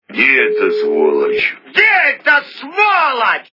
При прослушивании Особенности национальной охоты - Где эта сволочь качество понижено и присутствуют гудки.